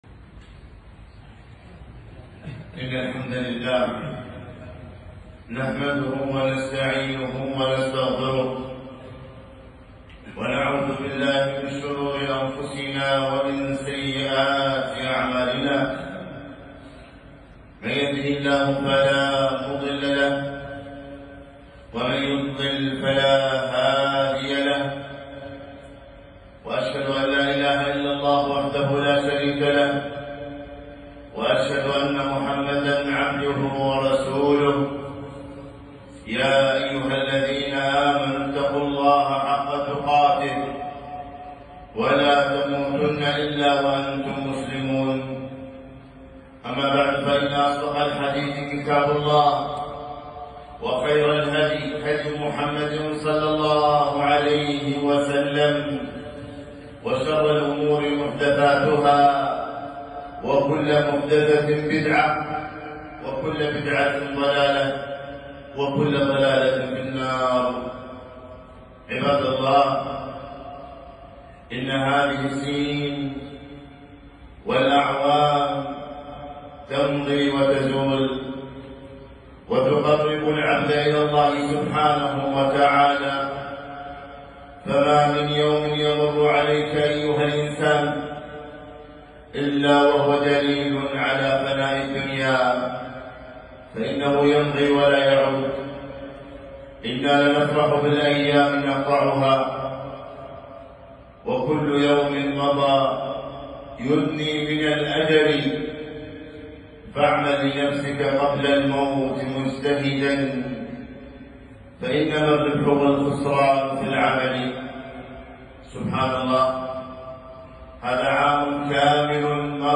خطبة - إطلالة عام هجري جديد